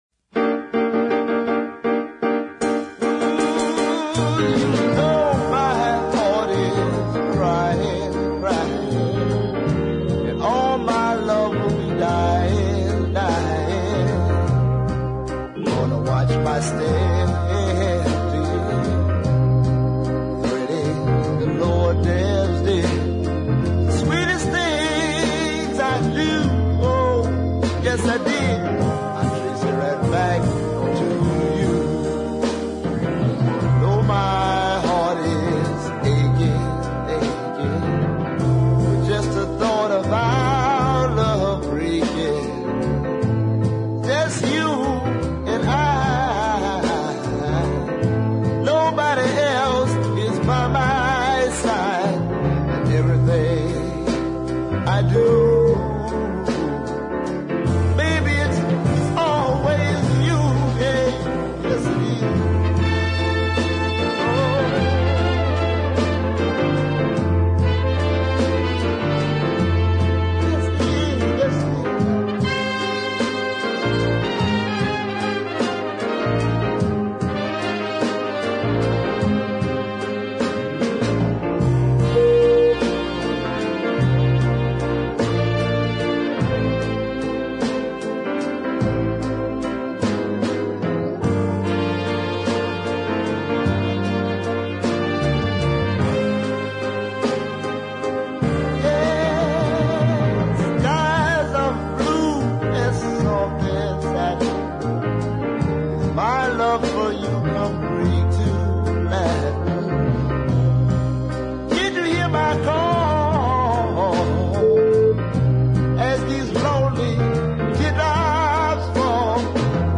features the same rather cheesy organ